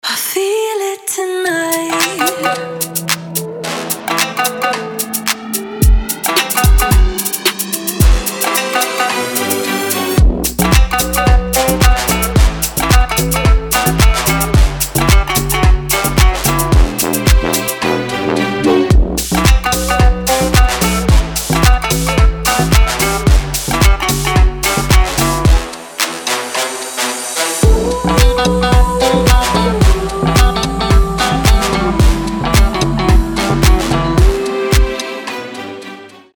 • Качество: 320, Stereo
deep house
мощные басы
скрипка
восточные
Bass House
красивый женский голос